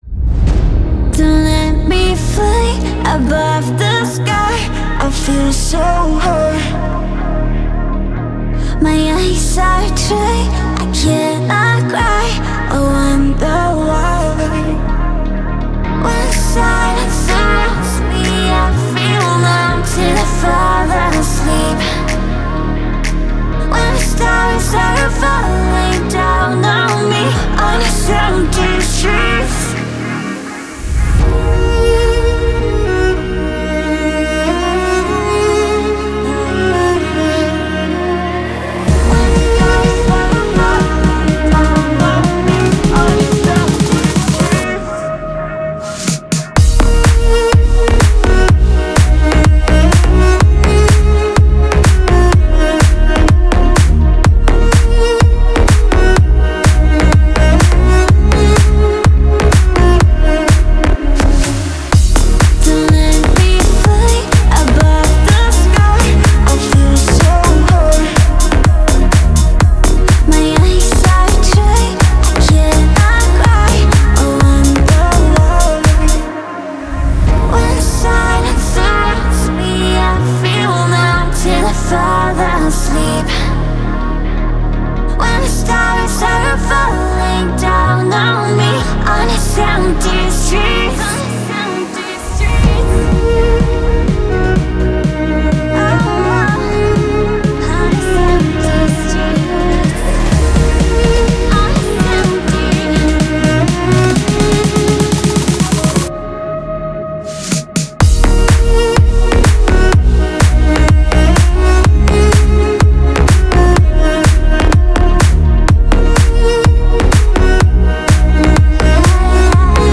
Категория: Deep House музыка